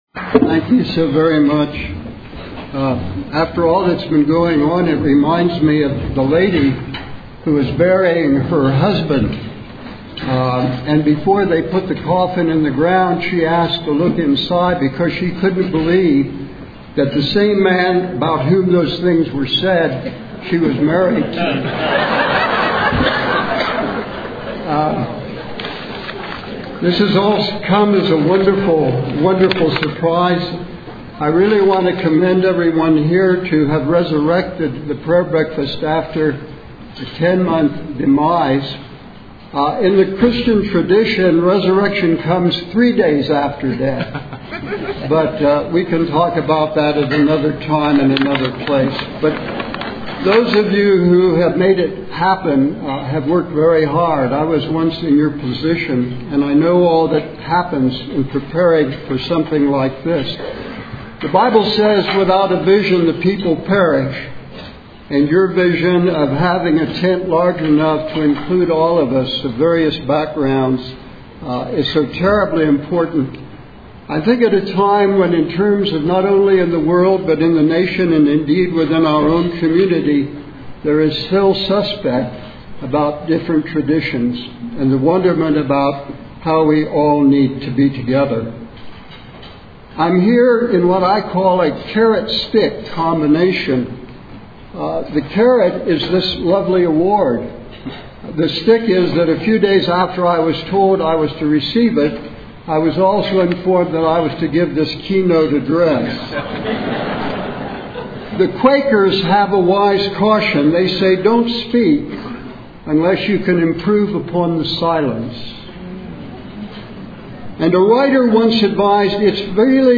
The Nevada Prayer Breakfast, sponsored by the Nevada Clergy Association, was held Tuesday, November 16, 2010, at 7:00 AM at the Atlantis Hotel. This powerful gathering brought together over 300 local people across a variety of religious communities to join in prayer for the good of the state of Nevada..